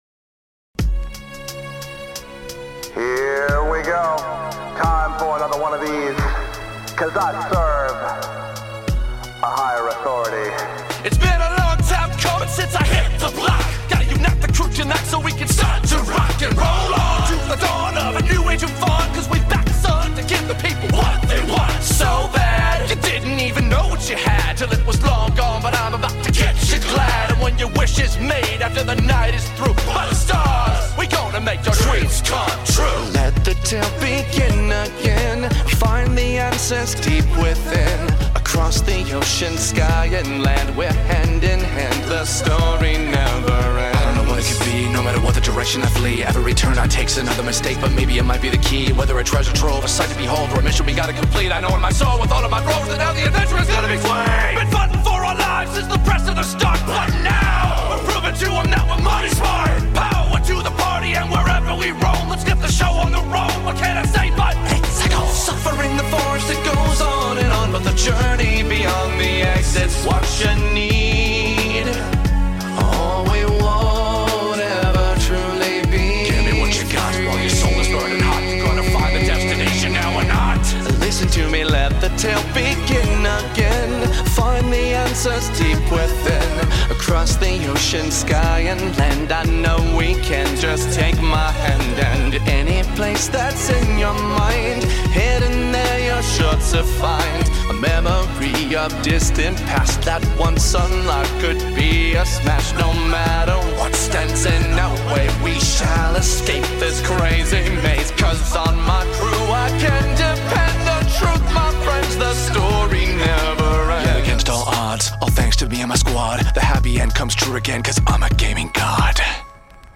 original rap song